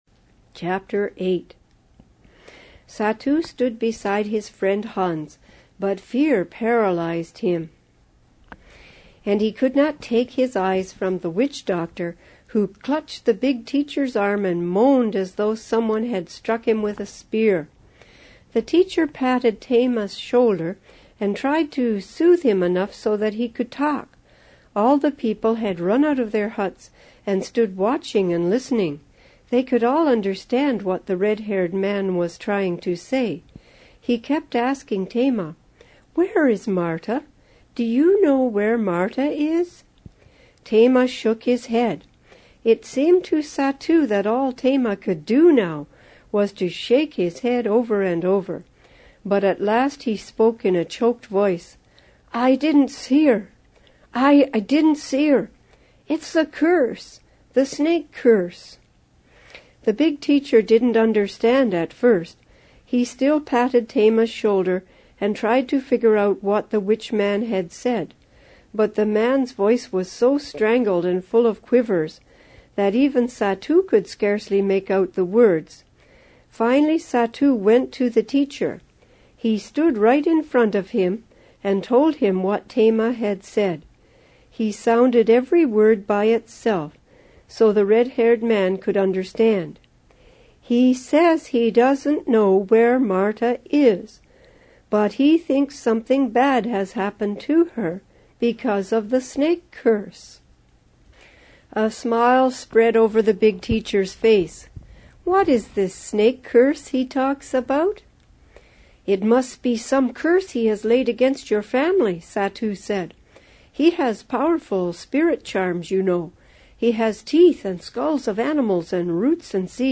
Granny Reads
An Exciting True Mission Story Book in Audio MP3